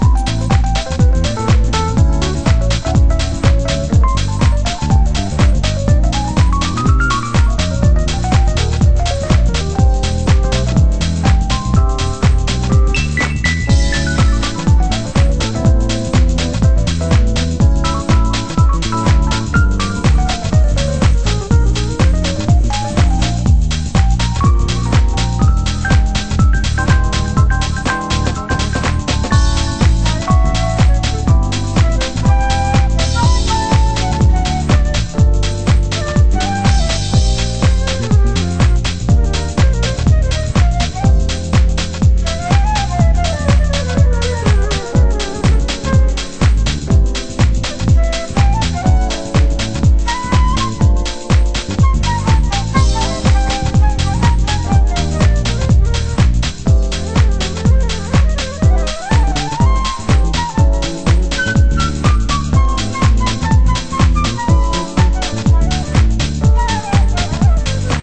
盤質：少しチリノイズ有　　ジャケ：良好